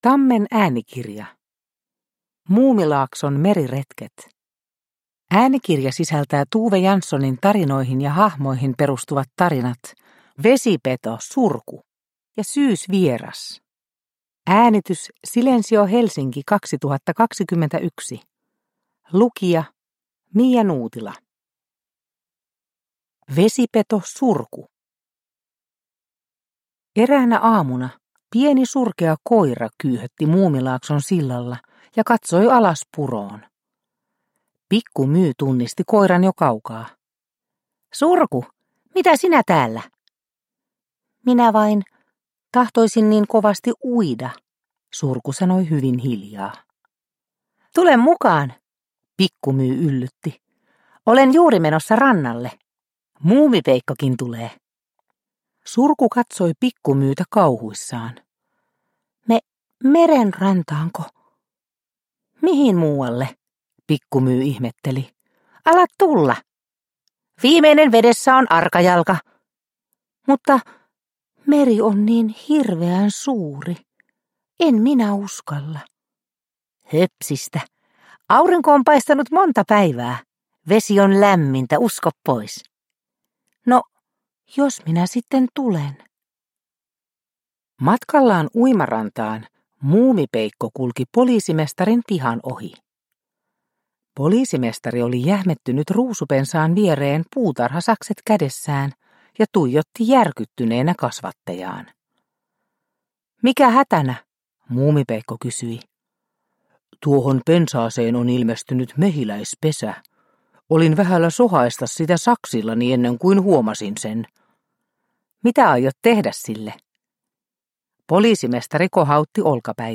Muumilaakson meriretket – Ljudbok – Laddas ner